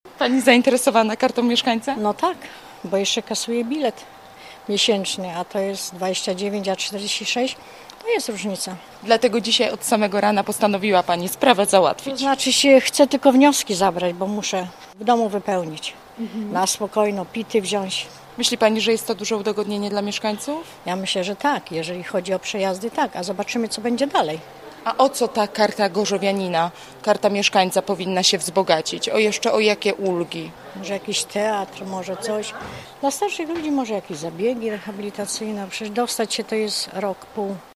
I na to także liczą mieszkańcy, którzy dziś rano pobierali wnioski w urzędzie przy Sikorskiego